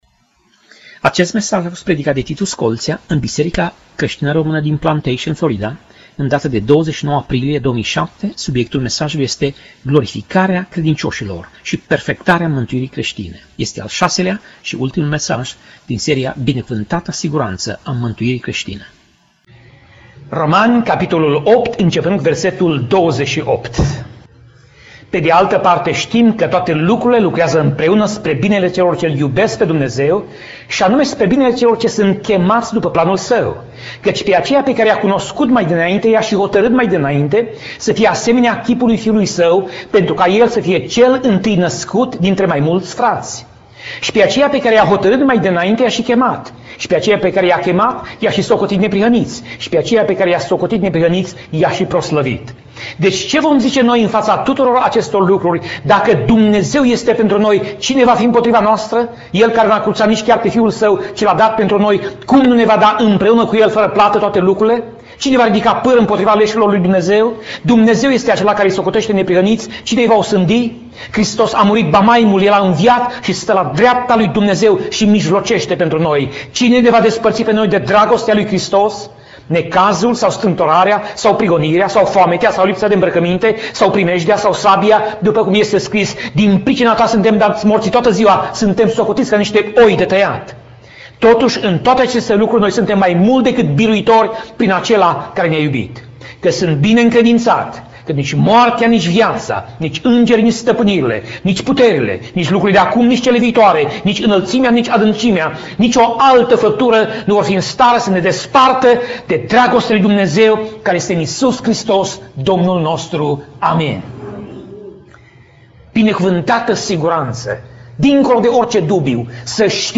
Pasaj Biblie: Romani 8:28 - Romani 8:30 Tip Mesaj: Predica